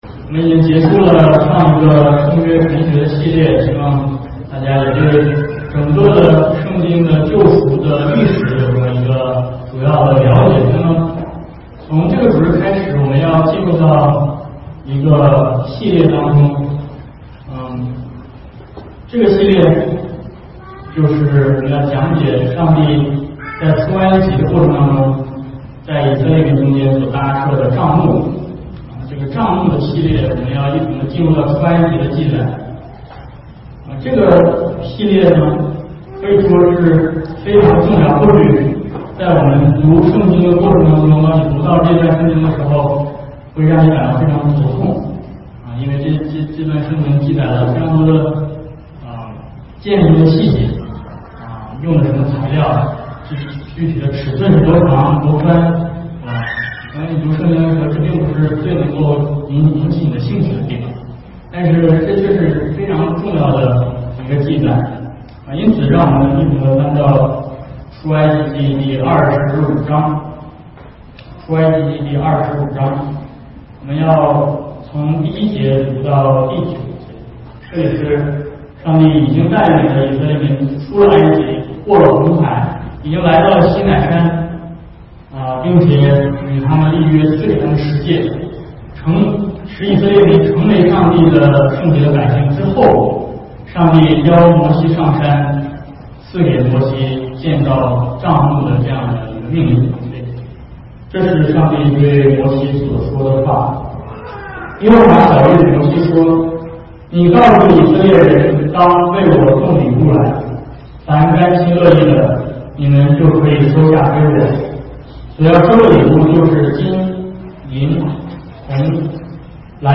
Service Type: 主日讲道